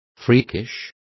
Complete with pronunciation of the translation of freakish.